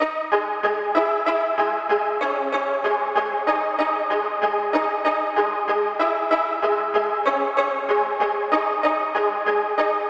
Tag: 95 bpm Trap Loops Synth Loops 1.70 MB wav Key : Unknown